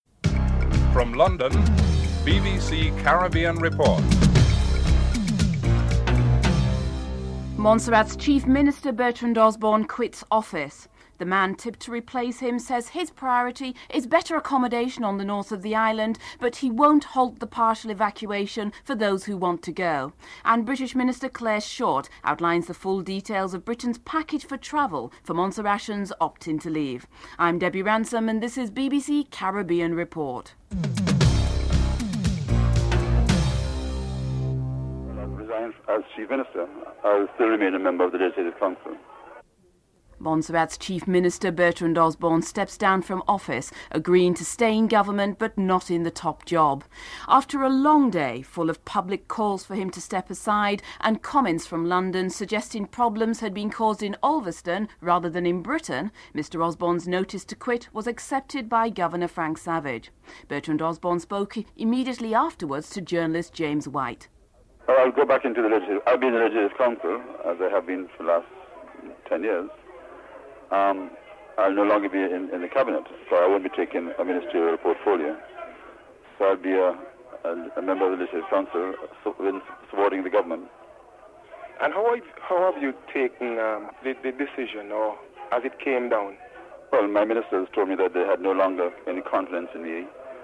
Headlines (00:00-00:33)
3. British Minister, Clare Short outlines in an interview the full details of the British travel package for Montserratians opting to leave(13:23-15:19)